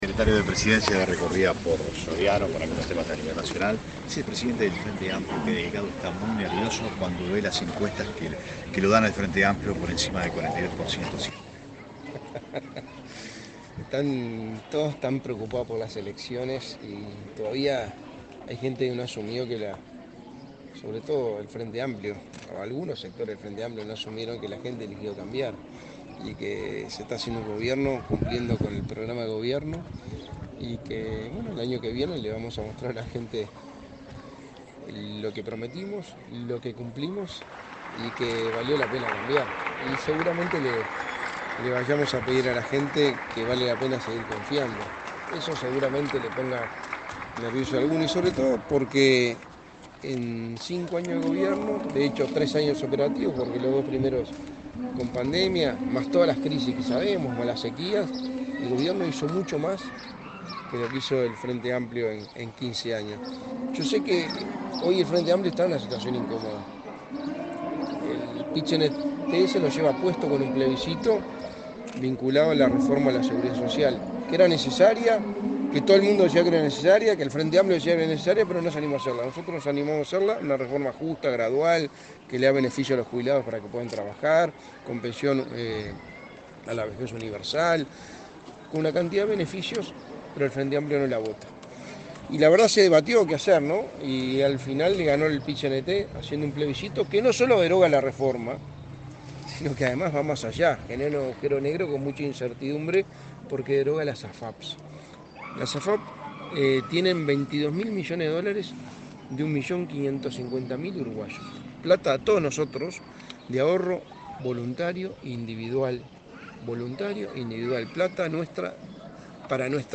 Declaraciones del secretario de Presidencia, Álvaro Delgado
Declaraciones del secretario de Presidencia, Álvaro Delgado 27/09/2023 Compartir Facebook X Copiar enlace WhatsApp LinkedIn Este miércoles 27, el secretario de la Presidencia, Álvaro Delgado, participó en la inauguración de un centro juvenil del Instituto del Niño y el Adolescente del Uruguay (INAU), en la localidad de Cardona, departamento de Soriano. Luego dialogó con la prensa.